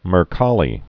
(mər-kälē, mĕr-)